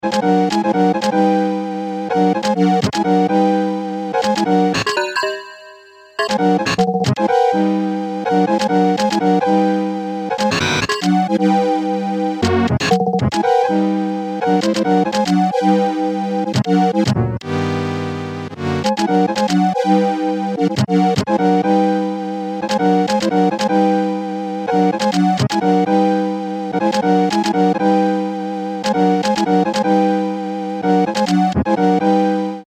3 LFOtrigger active
It also happens that the shorting sometimes alters the timbre.